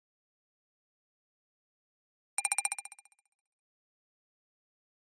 描述：带有振荡器的铃声和颤音效果
Tag: 合成器 贝尔 oscilator 振动